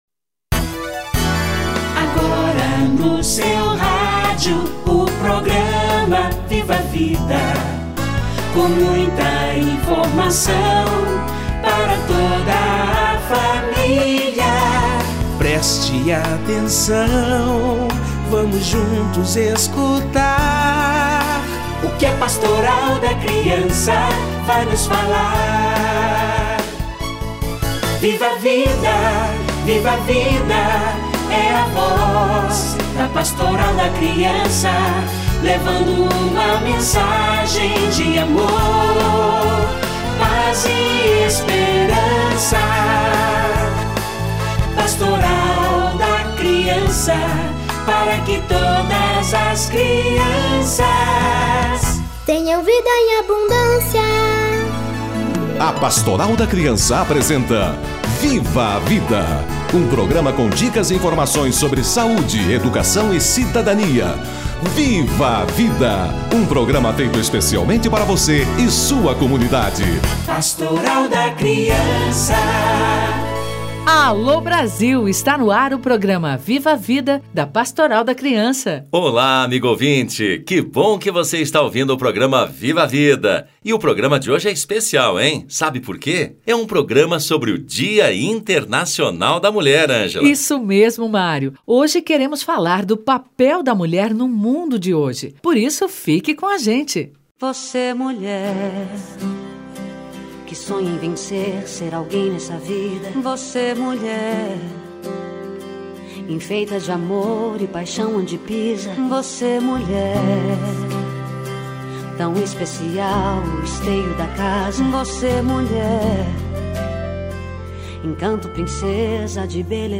Dia Internacional da Mulher - Entrevista